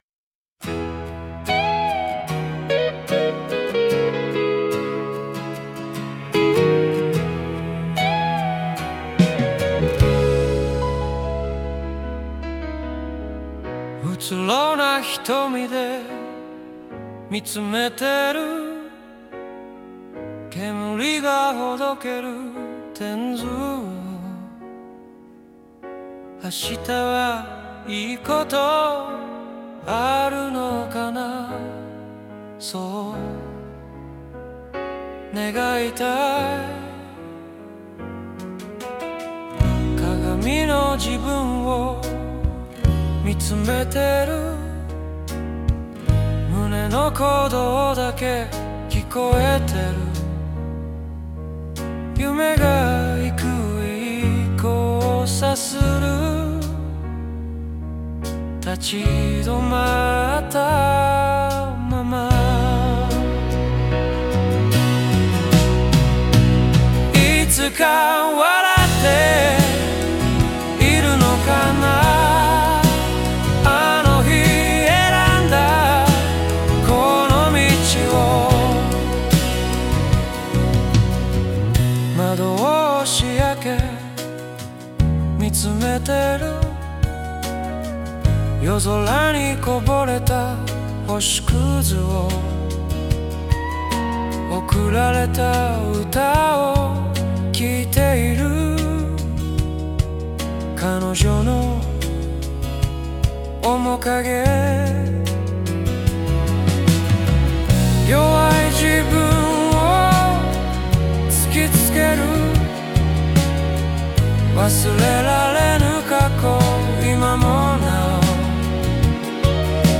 ＊有料版SNOW AIで作成しました。